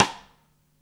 rim shot p.wav